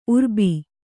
♪ urbi